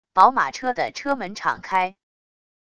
宝马车的车门敞开wav音频